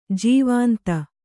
♪ jīvānta